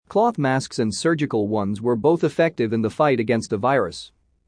このままの速度でお聞きください。
ディクテーション第1問
【ノーマル・スピード】